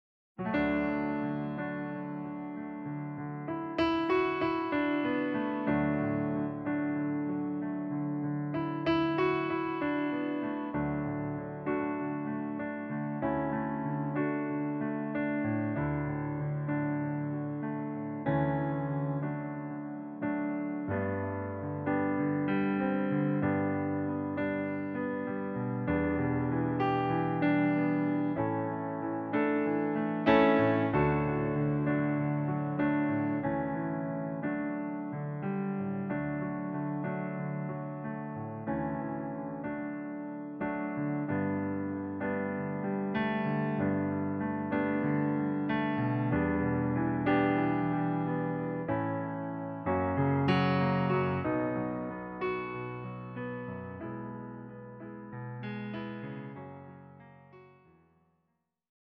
Demo in D-Dur